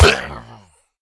Minecraft Version Minecraft Version 25w18a Latest Release | Latest Snapshot 25w18a / assets / minecraft / sounds / mob / illusion_illager / death1.ogg Compare With Compare With Latest Release | Latest Snapshot
death1.ogg